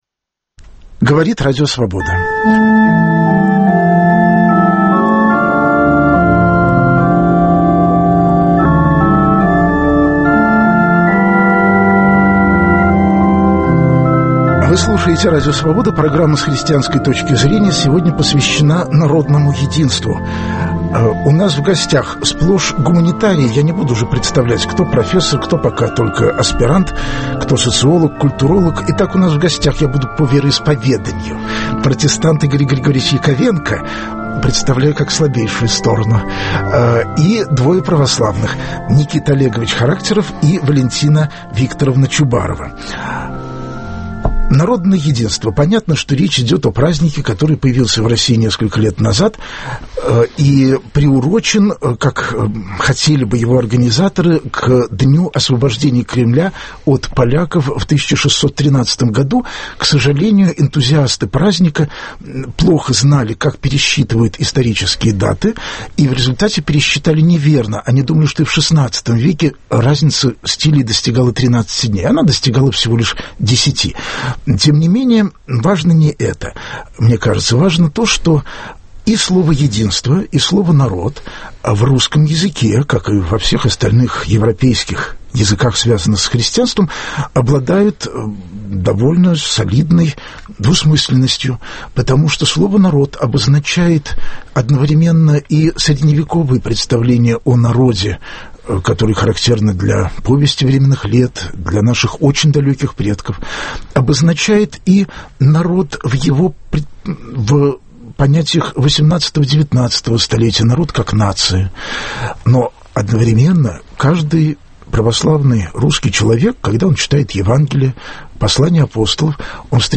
А в чём различие церковного представления о народе и единстве от политического? Это в прямом эфире будут обсуждать христиане разных конфессий.